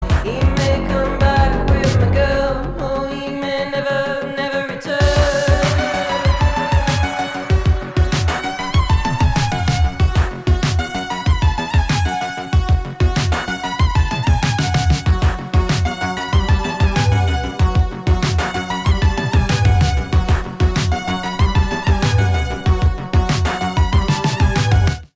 • Качество: 320, Stereo
Electronic
club
minimal